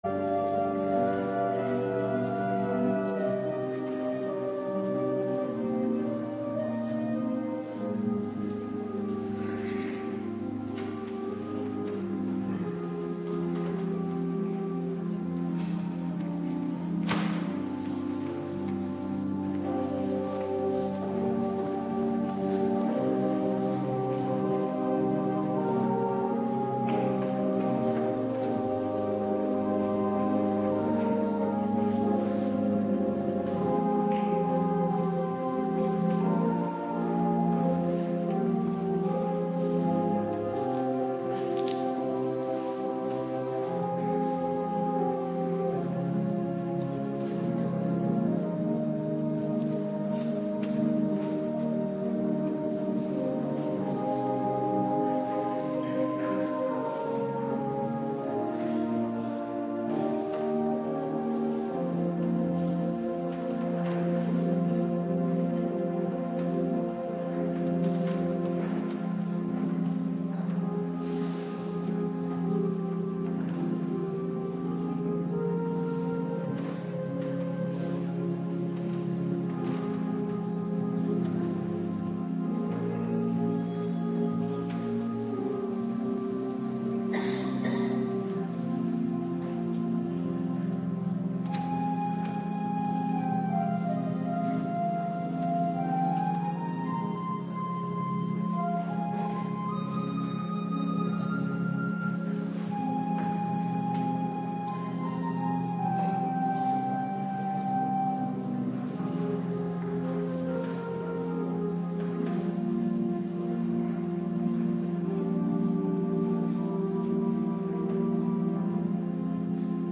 Morgendienst Heilige Doop - Cluster 2
Locatie: Hervormde Gemeente Waarder